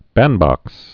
(băndbŏks)